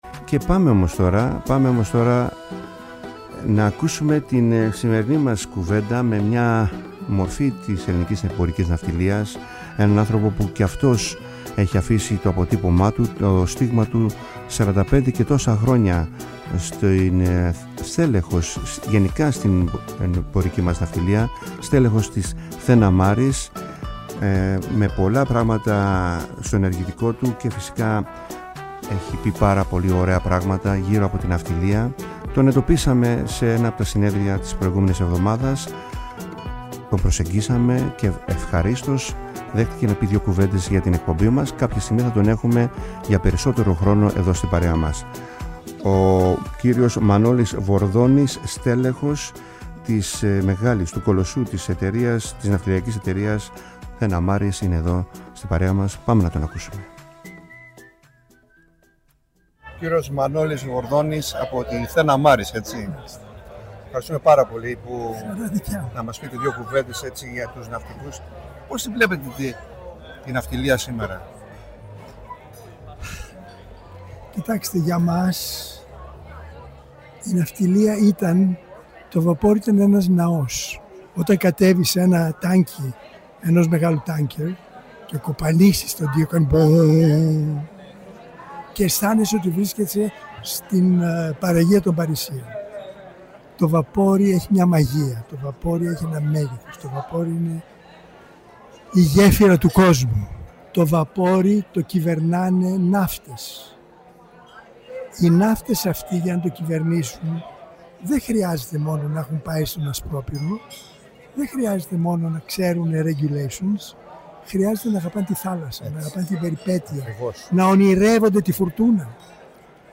Επιμέλεια – παρουσίαση